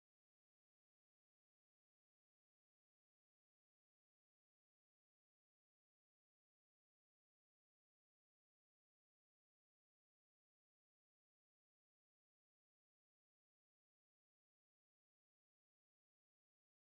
Here's a cleaner sample, 16 seconds of silence:
test1.flac:   FLAC audio bitstream data, 24 bit, stereo, 44.1 kHz, length